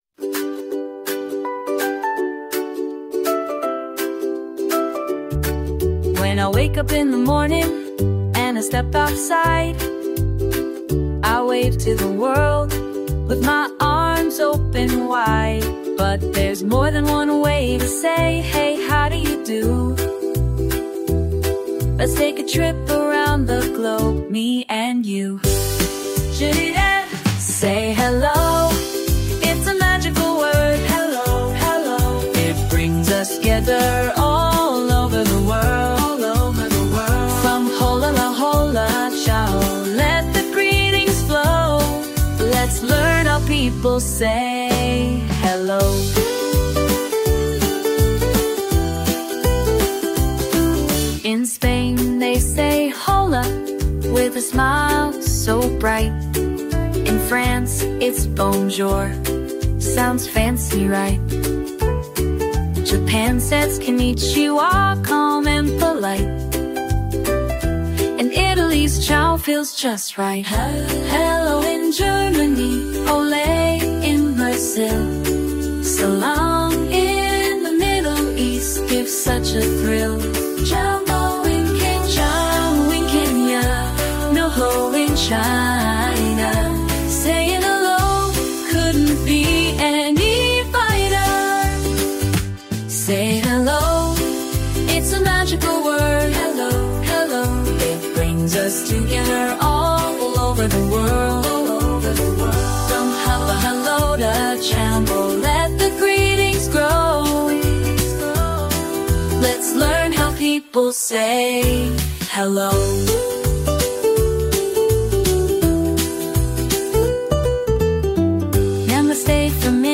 Key: C Major